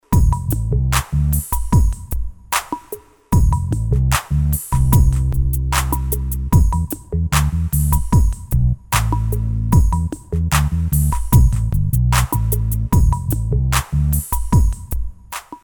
Royalty-free Chillout background music